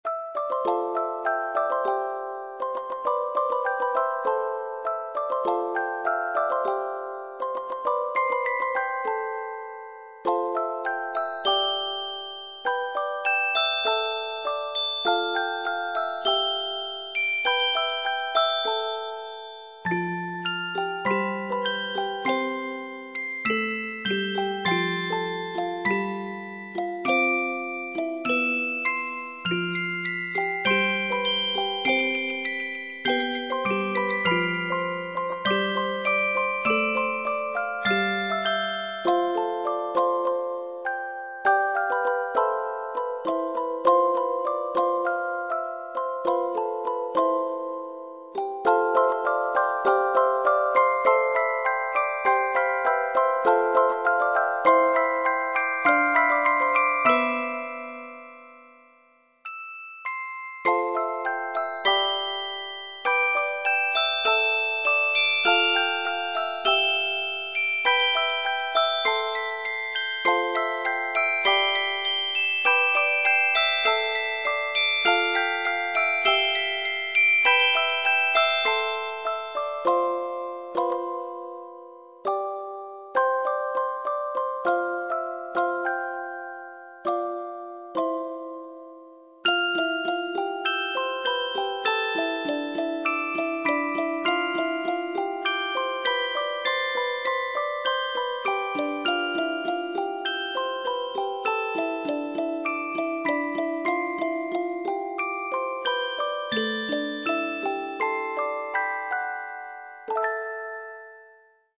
オルゴール
もう消してしまいましたが、鬼男が閻魔に対して、というイメージで曲を作ってたやつを、オルゴールにしました。
オルゴールにしちゃトラック数多いとは思うけど（一応ちっとは削った）よかったらどうぞ。
▽22500hz/モノラルのmp3（約440KB）→
mp3は音源を別にして録音しているので、MIDIとは音色が違います。